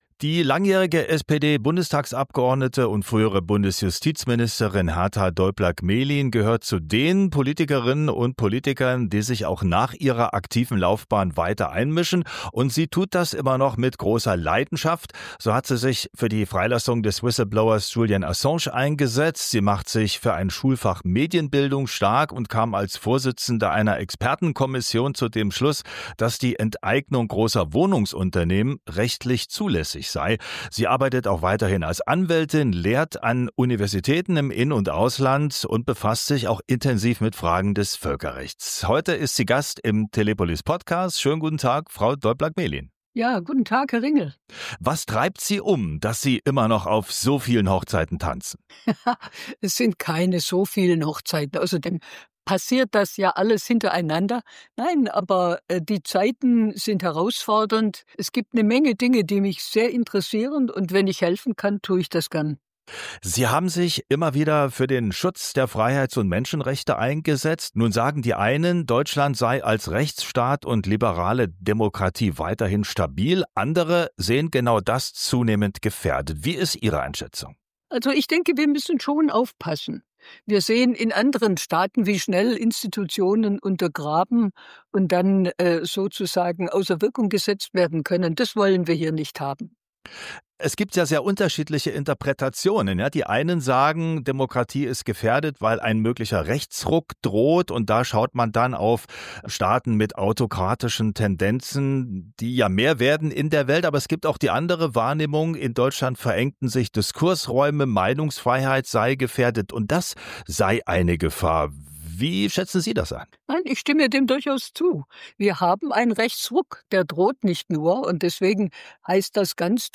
Die frühere Bundesjustizministerin Herta Däubler-Gmelin (SPD) sieht die Demokratie in Deutschland durch einen drohenden Rechtsruck und die Macht der Tech-Giganten gefährdet. Im Interview mit Telepolis fordert sie eine stärkere Regulierung sozialer Medien, um die Meinungsfreiheit zu schützen und Mani...